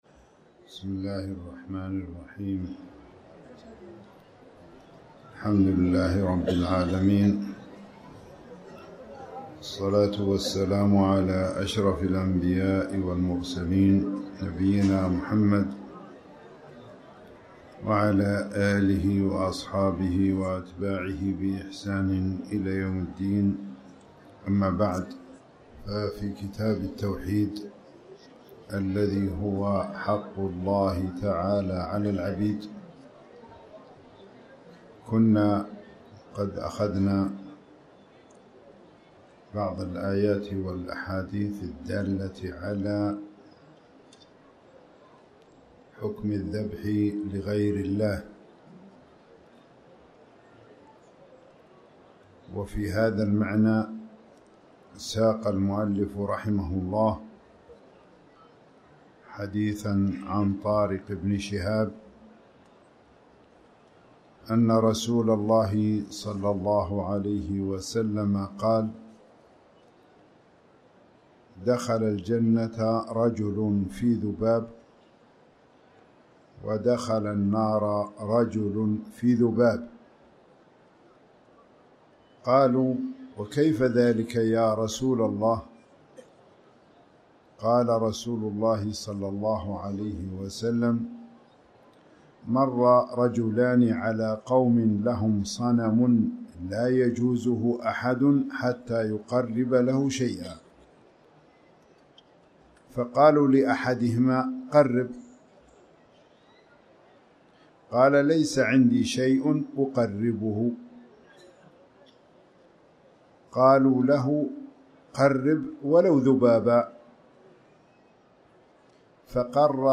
تاريخ النشر ٢٩ رجب ١٤٣٩ هـ المكان: المسجد الحرام الشيخ